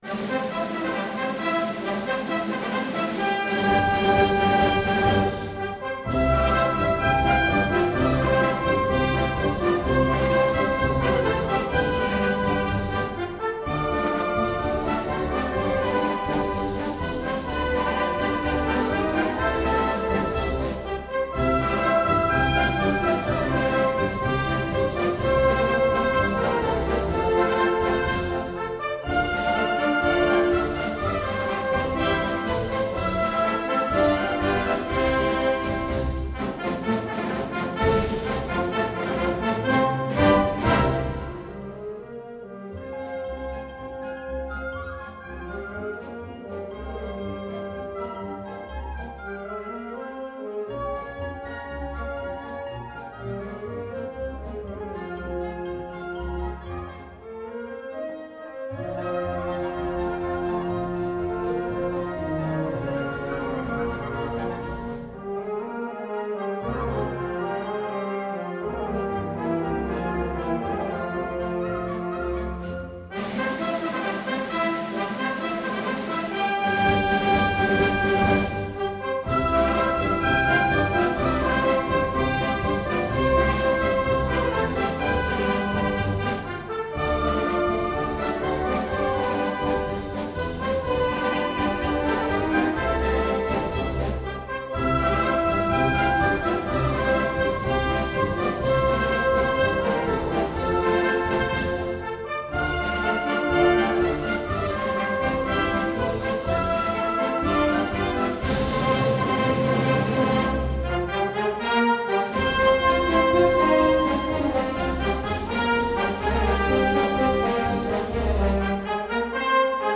pasodoble